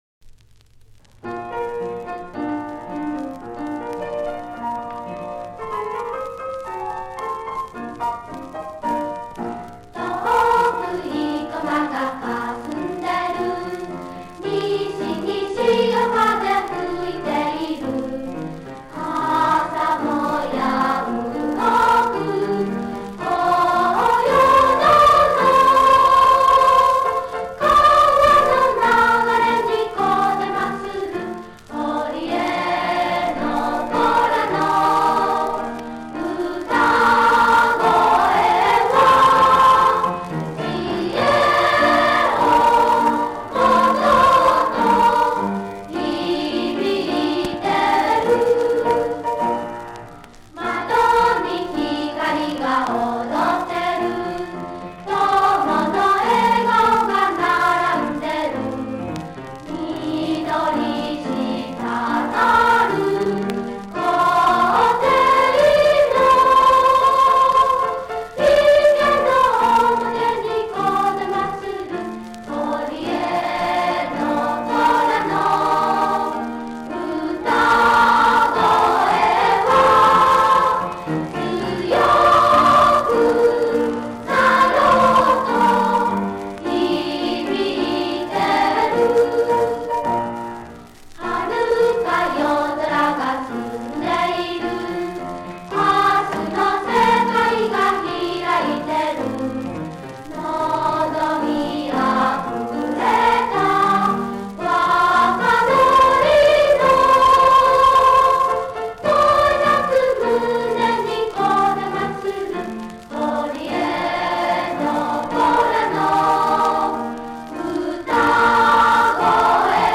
昭和３７年３月９日の本校復校１０周年記念式典で児童たちが披露した合唱や演奏の記録です。
６年生「校歌」合唱 ６年生校歌合唱（復校10周年記念レコード） ２年生「四季」演奏 ２年生四季演奏（復校10周年記念レコード）